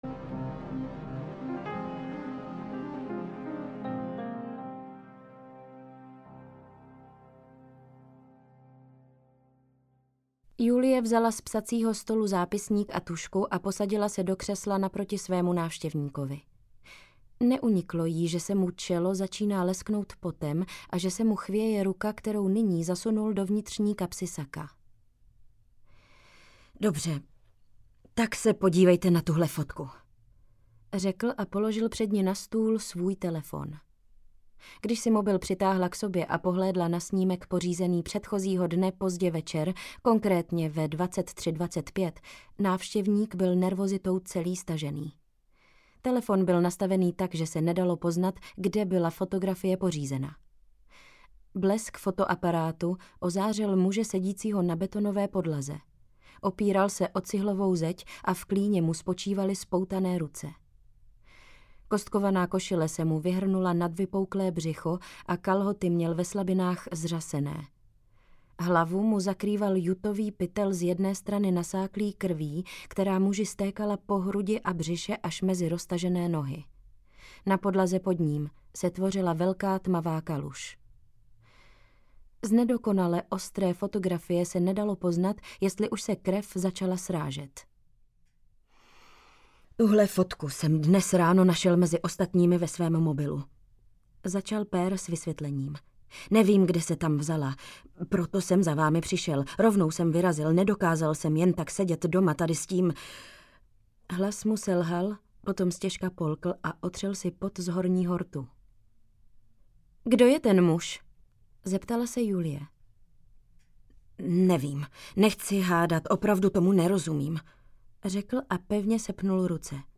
Až najdu klíč audiokniha
Ukázka z knihy
az-najdu-klic-audiokniha